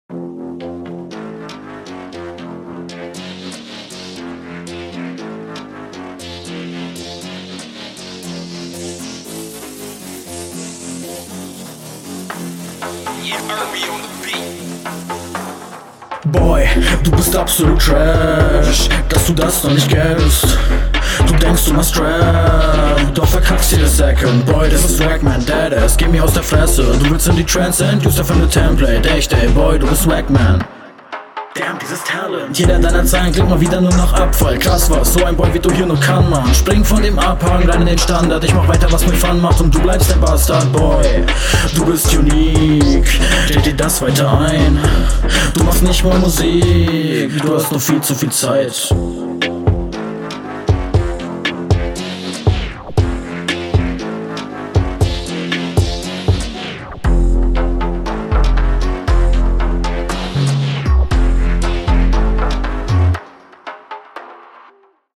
Du steigst ziemlich weird ein; hatte zunächst noch Hoffnung, dass sich das legt, allerdings klingt …
Flow: Flowlich ist das ziemlich nice.
Flow: Flow ist ganz cool.